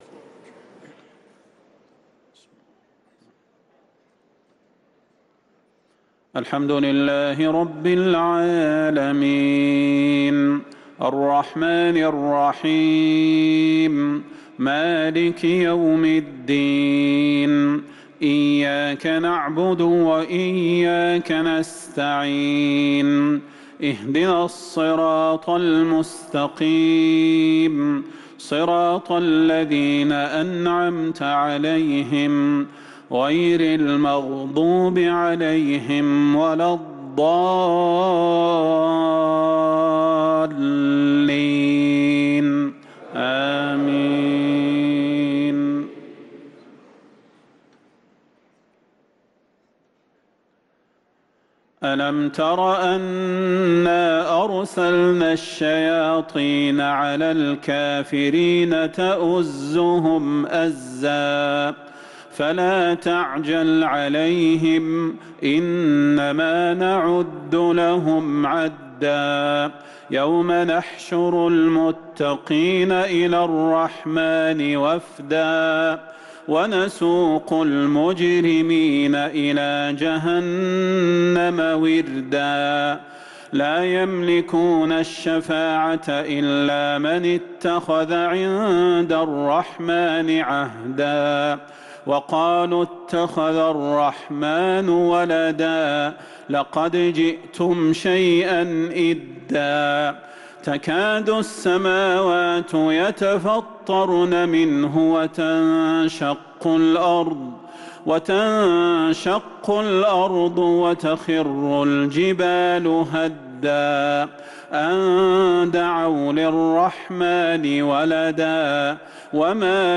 صلاة العشاء للقارئ صلاح البدير 24 ربيع الأول 1445 هـ
تِلَاوَات الْحَرَمَيْن .